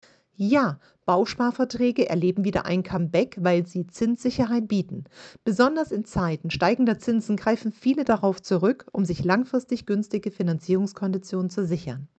Sie ist Immobilienexpertin und Maklerin.